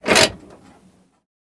handbrake_up.ogg